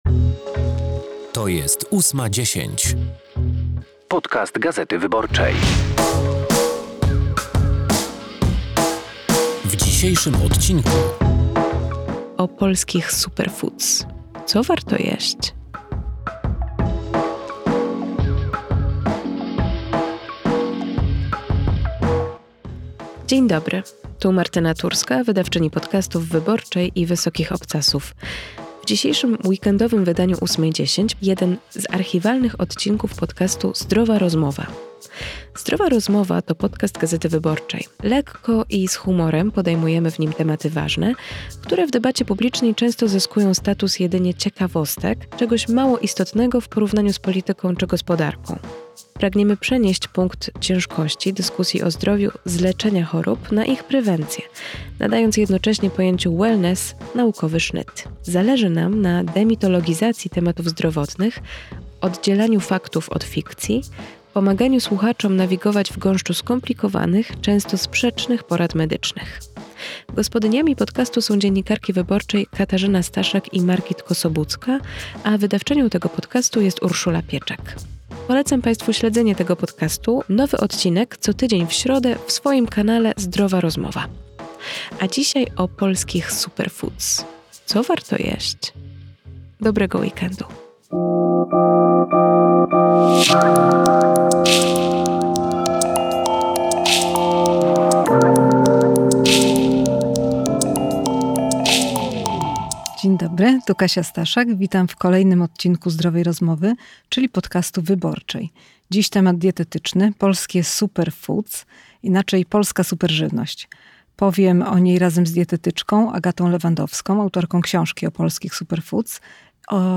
rozmawia z Cezarym Tomczykiem, wiceministrem obrony narodowej i wiceprzewodniczącym Platformy Obywatelskiej, o szczegółach akcji pomocowej "Feniks" na terenach popowodziowych. Jaka jest wartość zniszczeń spowodowanych przez powódź? Ilu żołnierzy jest zaangażowanych w akcję pomocową?